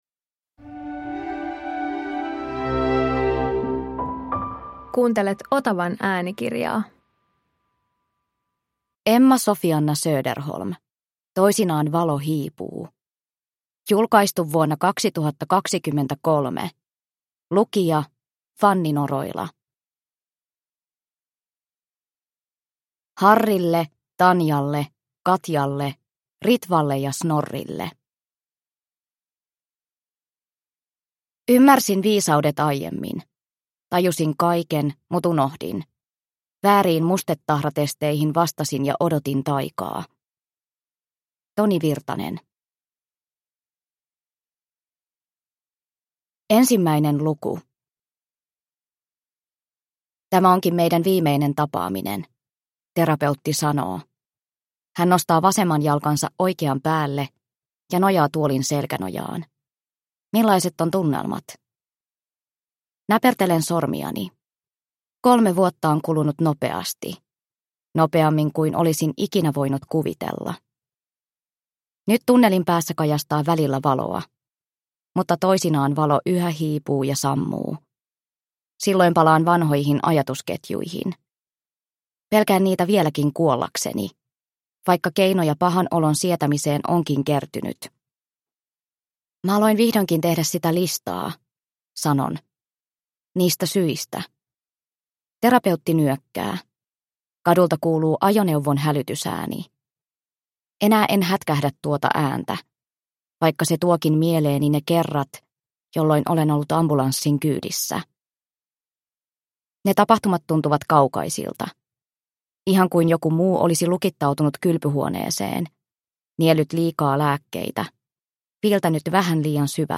Toisinaan valo hiipuu – Ljudbok – Laddas ner